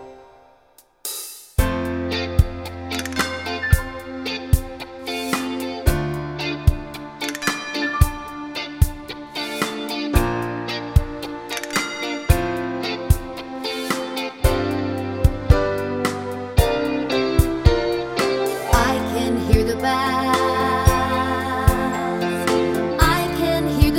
Minus Bass Soundtracks 4:12 Buy £1.50